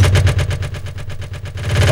02_25_drumbreak.wav